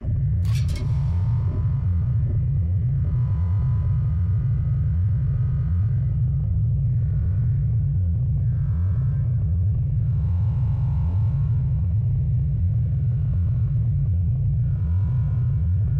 发动机专业
描述：fx引擎，我做了它的理由。
Tag: 120 bpm Ambient Loops Fx Loops 2.69 MB wav Key : C